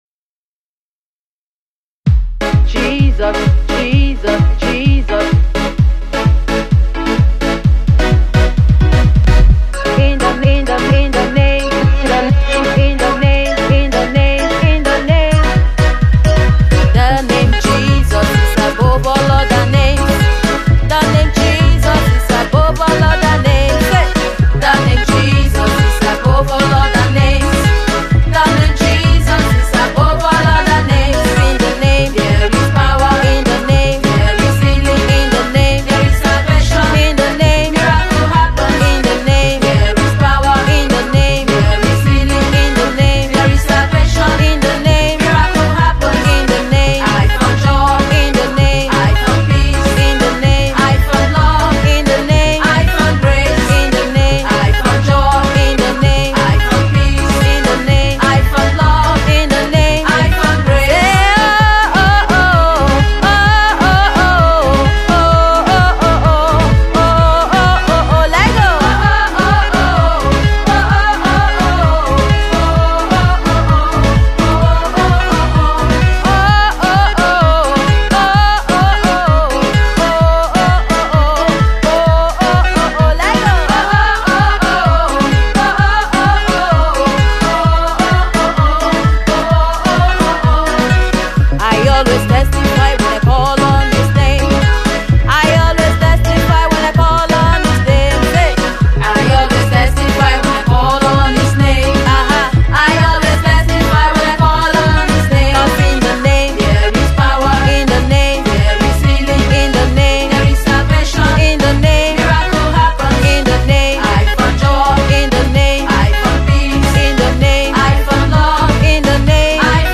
gospel
praise jamz